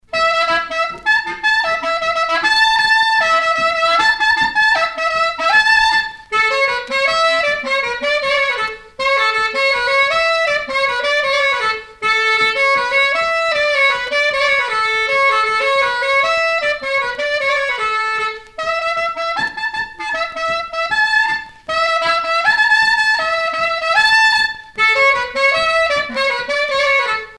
Sonneurs de clarinette en Bretagne
Pièce musicale éditée